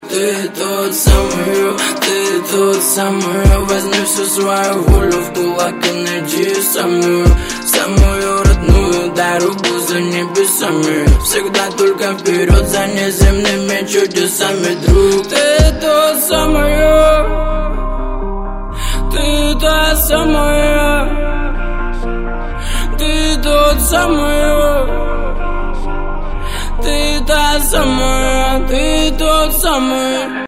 Категория: Рэп рингтоны